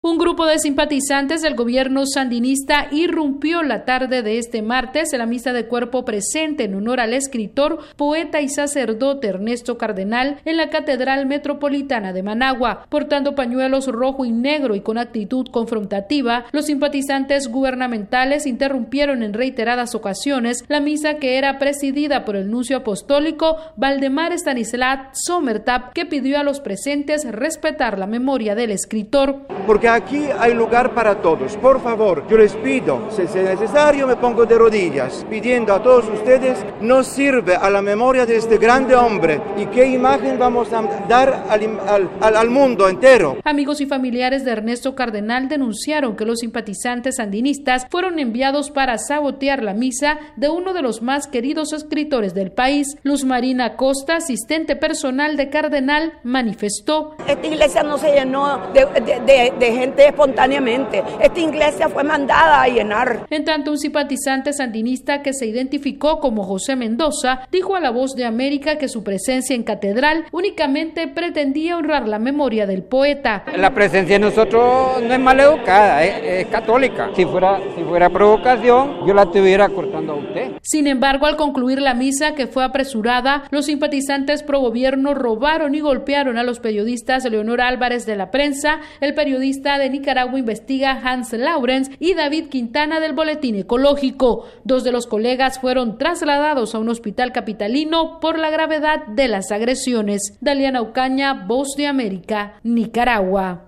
VOA: Informe de Nicaragua